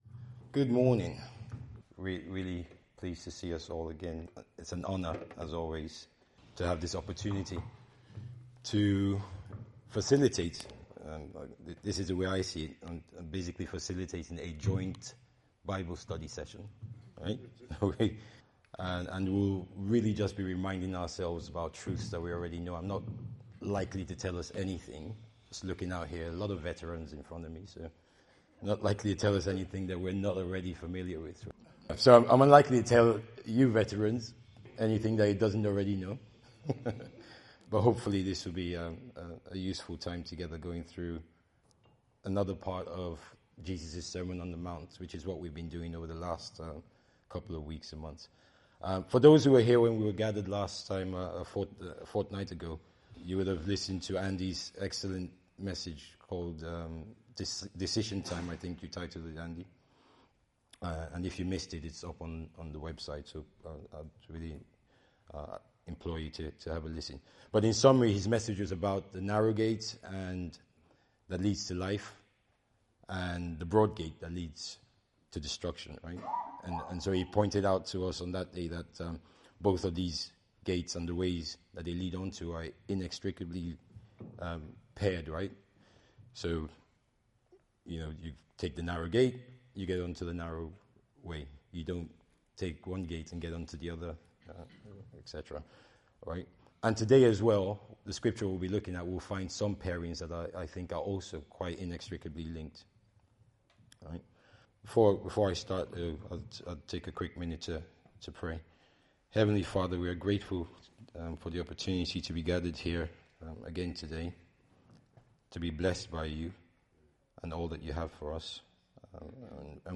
Teachings from our Sunday that don’t form part of a series.